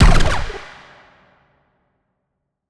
TM88 FunkKick5.wav